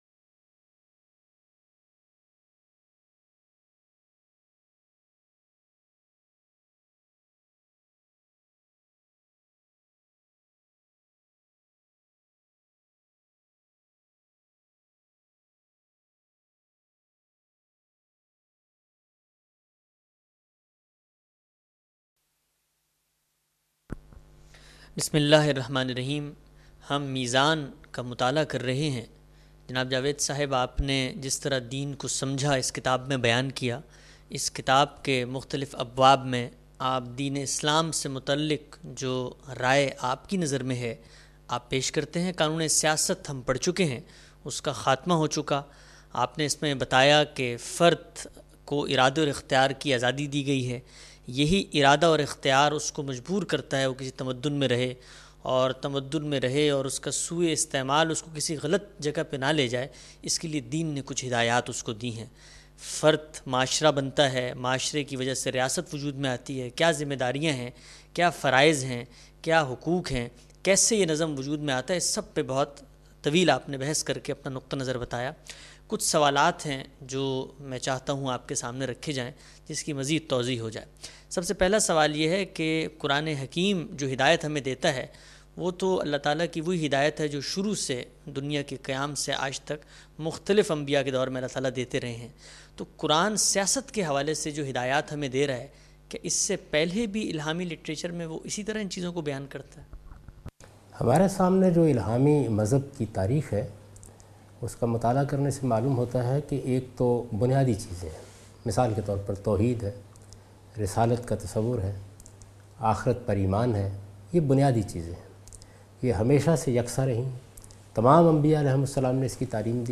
A comprehensive course on Islam, wherein Javed Ahmad Ghamidi teaches his book ‘Meezan’.
In this lecture he teaches the topic 'The Political Shari'ah' from 2nd part of his book. This sitting is a question answer session in which Ustazz Javed Ahmed Ghamidi answers important questions about his understanding of social shari'ah.